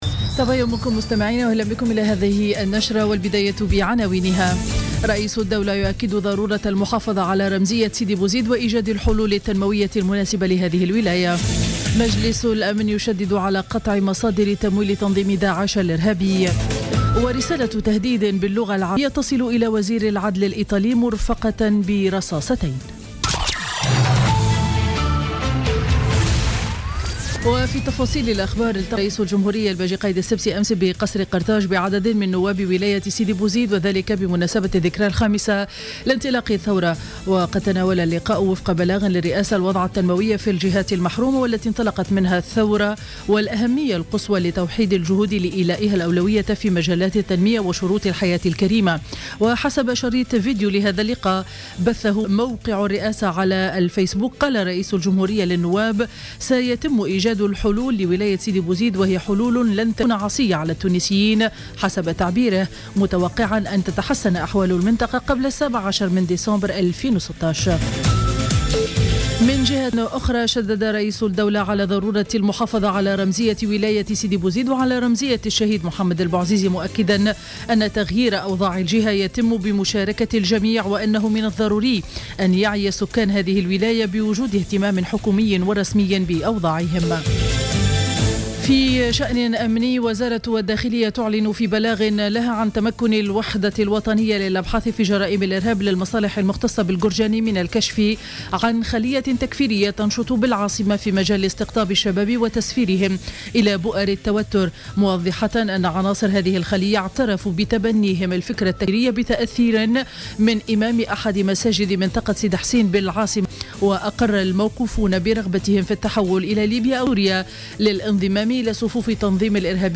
نشرة أخبار السابعة صباحا ليوم الجمعة 18 ديسمبر 2015